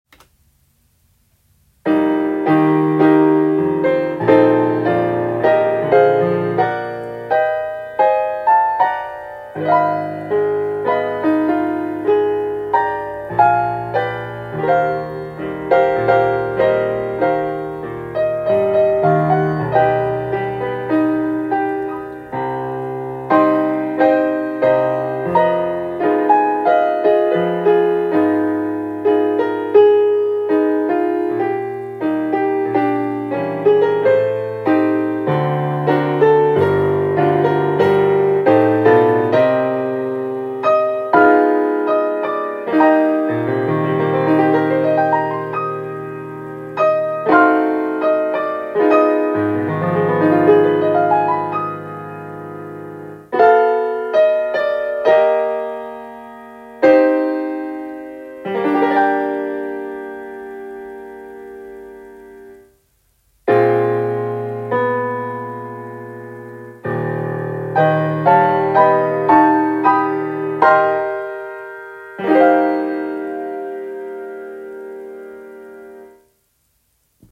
SOPRANO (Mezzo)
Track to tape with: